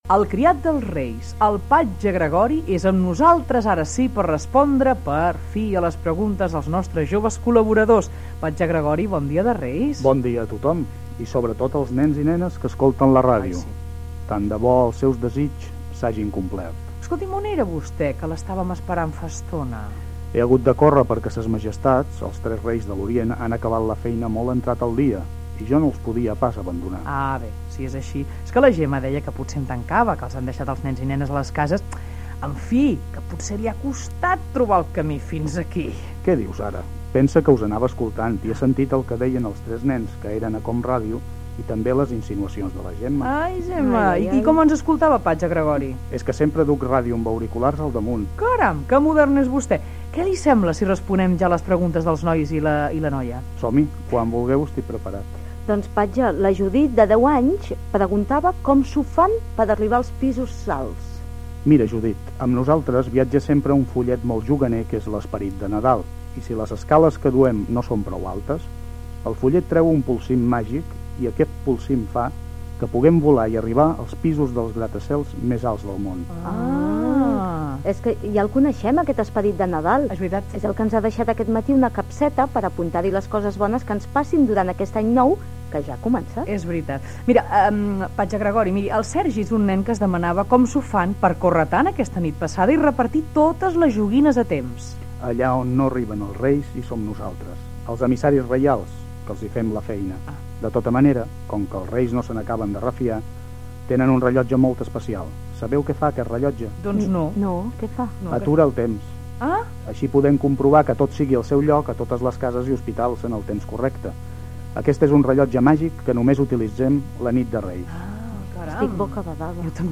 Visita del patge Gregori, després de la nit de Reis, que respon les preguntes dels infants.